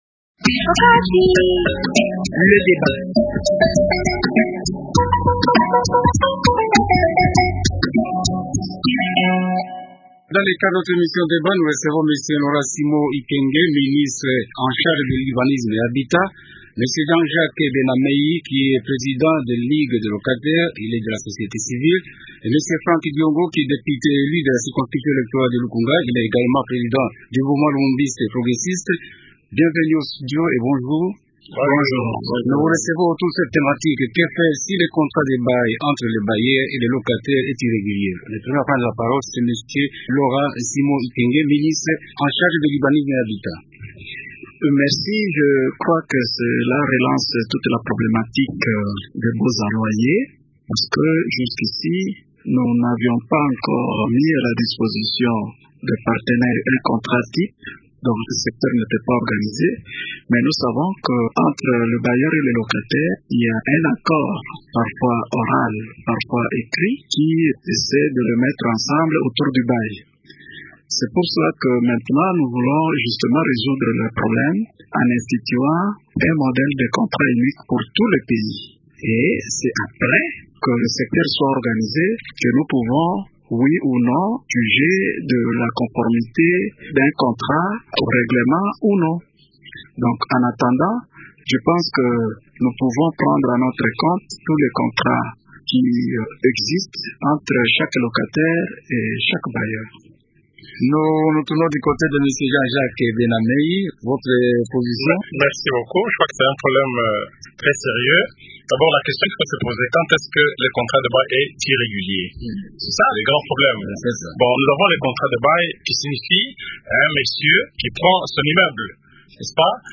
Invités :rn-Franck DIONGO président du MLP et représentant l’opposition ;rn-Laurent-Simon IKENGE ministre de l’urbanisme rnet habitat et représentant la majorité au pouvoir ;